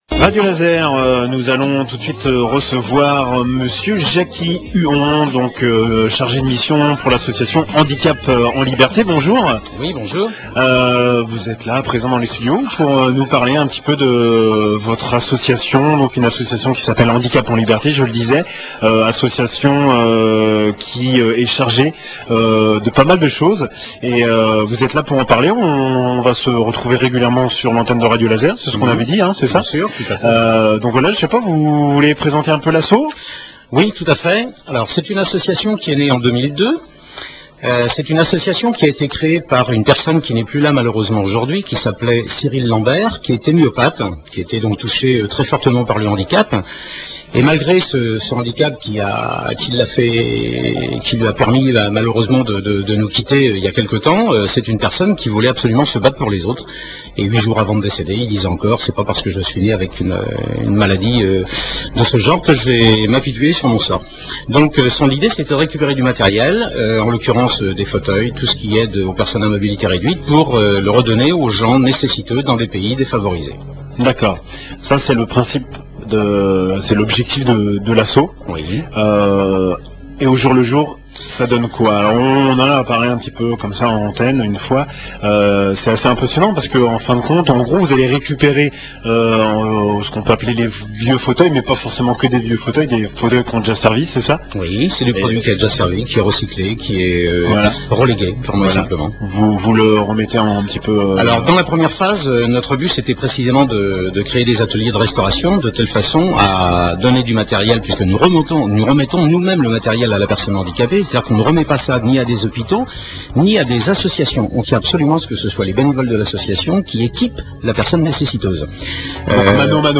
Radio Laser , notre nouveau partenaire nous ouvre l'antenne. Un reportage est pr�vu par mois.